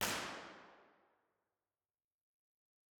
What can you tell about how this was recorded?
impulseresponseheslingtonchurch-004.wav